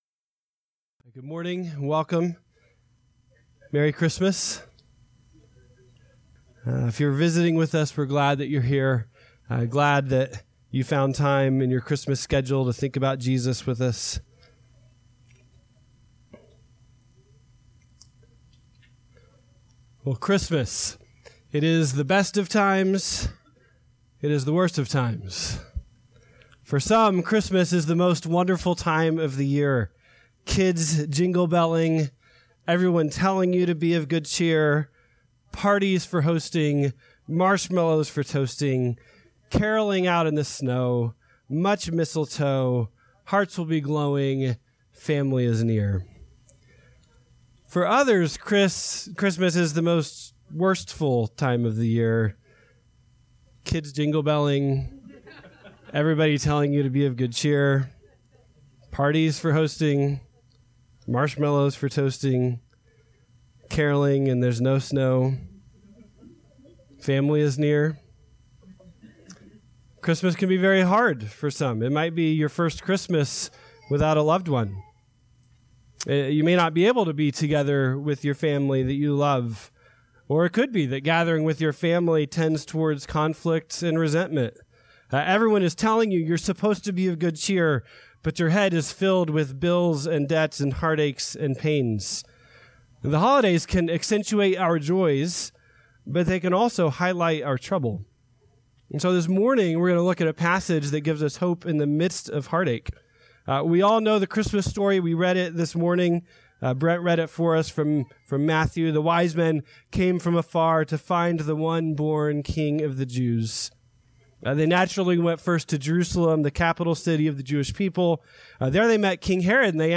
December 22, 2019 (Sunday School)